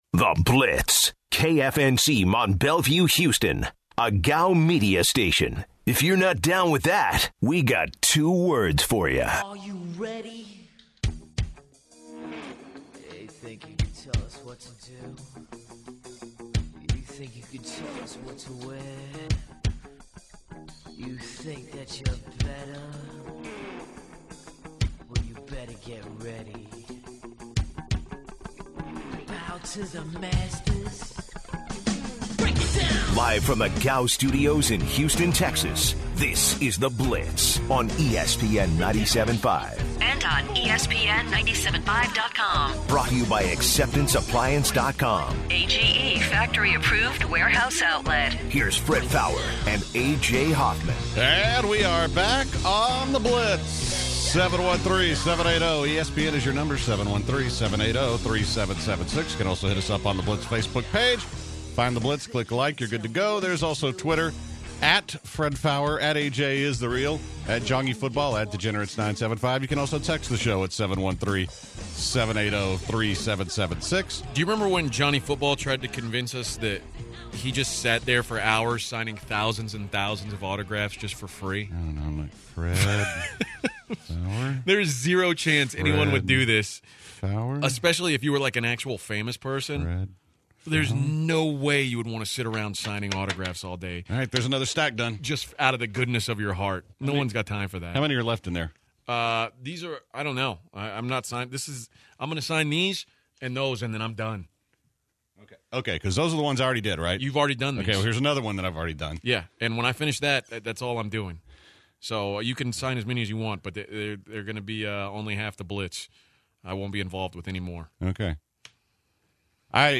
To open the second hour the guys discuss Blount season ending injury, NBA trades and the season finale of Fargo.